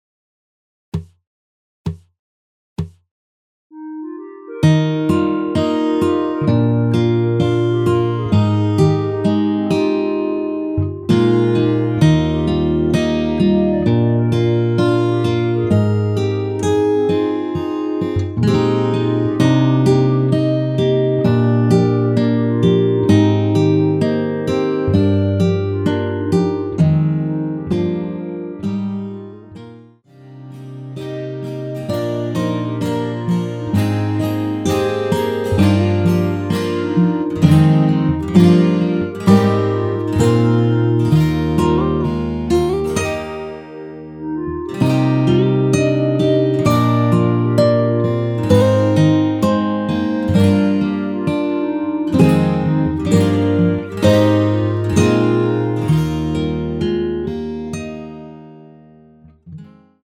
전주없이 시작 하는 곡이라 카운트 넣어 놓았습니다.(미리듣기 참조)
원키(1절+후렴)으로 진행되는 멜로디 포함된 MR입니다.
Eb
앞부분30초, 뒷부분30초씩 편집해서 올려 드리고 있습니다.
중간에 음이 끈어지고 다시 나오는 이유는